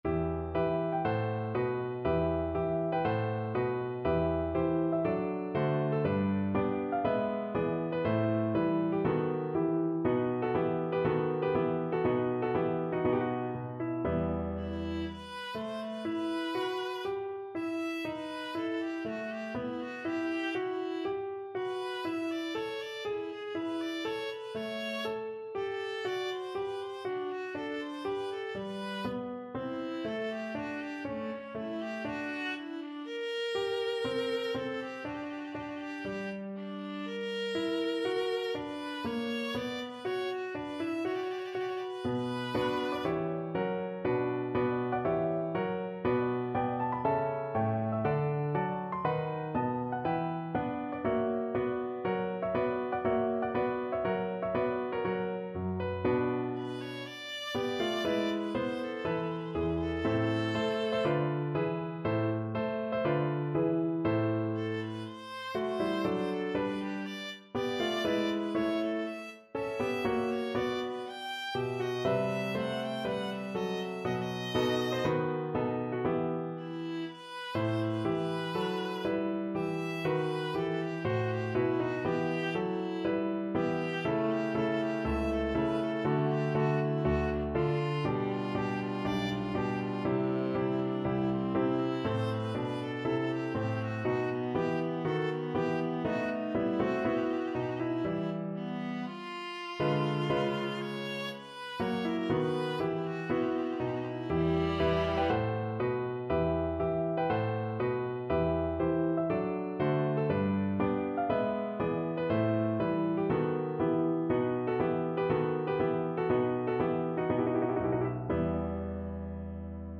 Viola
4/4 (View more 4/4 Music)
E minor (Sounding Pitch) (View more E minor Music for Viola )
=60 Andante
Classical (View more Classical Viola Music)